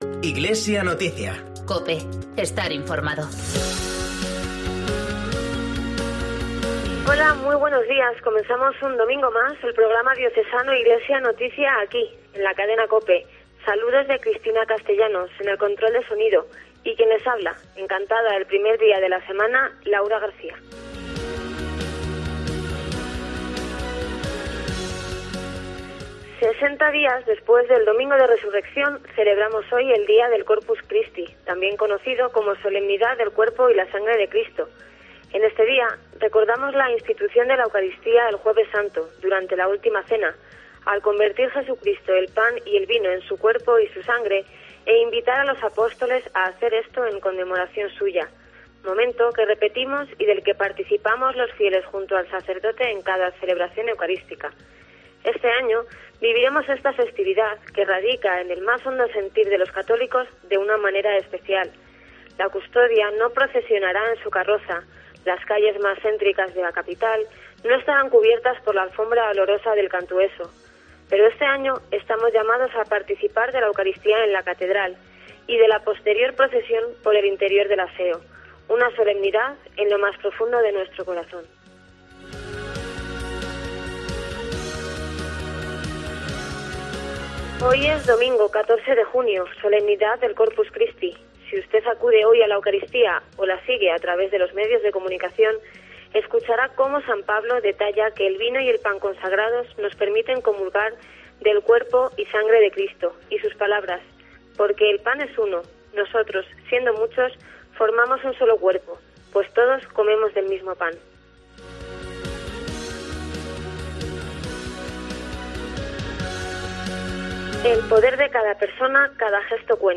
Programa semanal de información cristiana